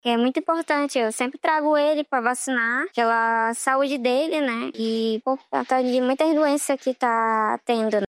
SONORA-2-AMPLIACAO-VACINA-INFLUENZA-.mp3